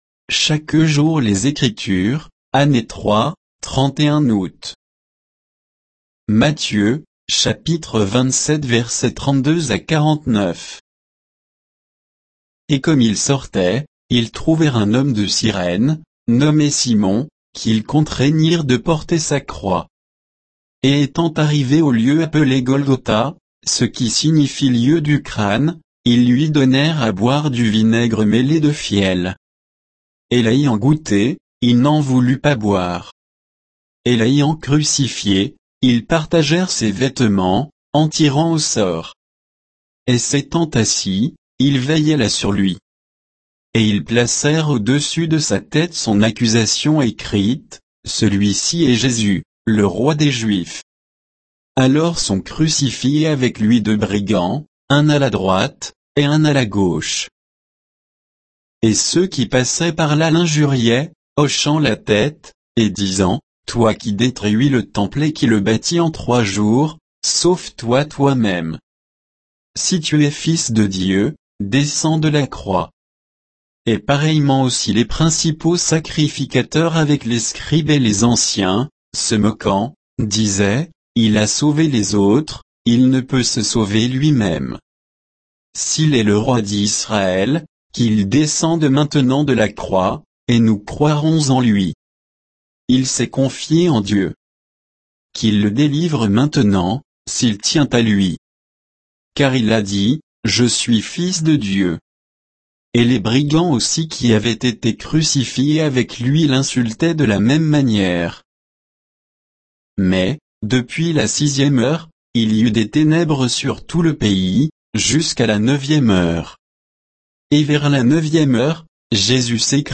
Méditation quoditienne de Chaque jour les Écritures sur Matthieu 27